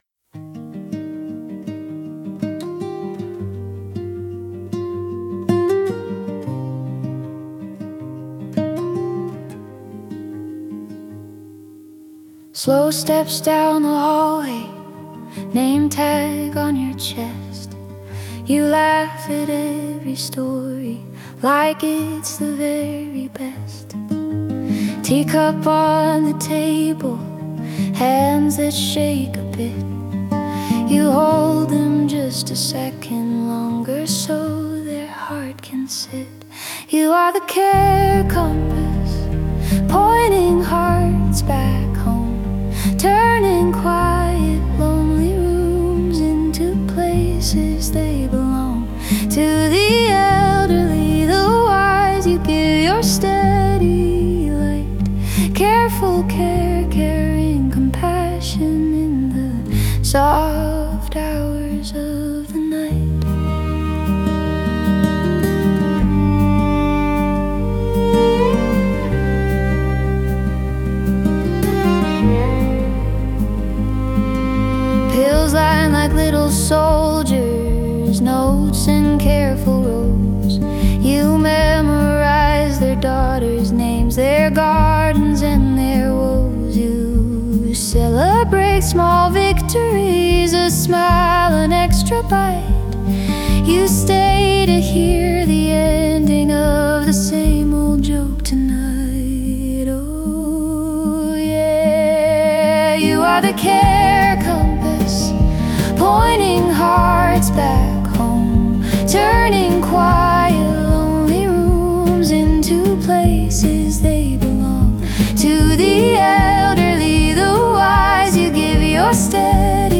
Dramatic Emotional Inspirational Motivational